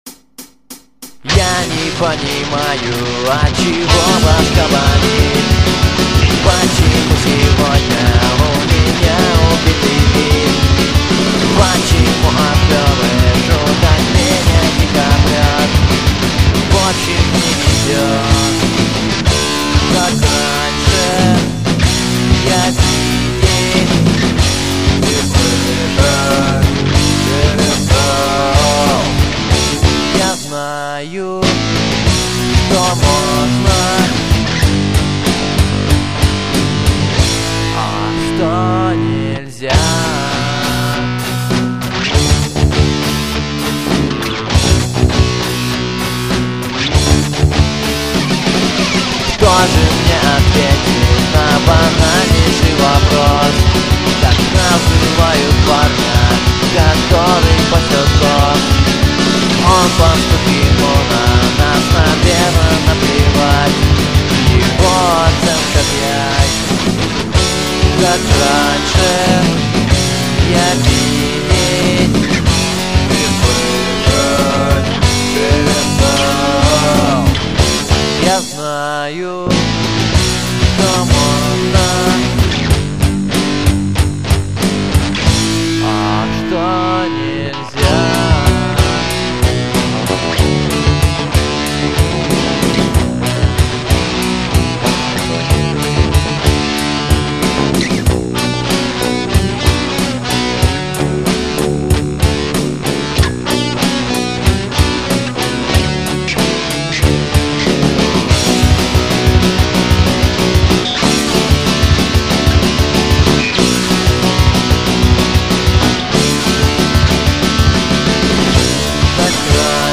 панк-рок группы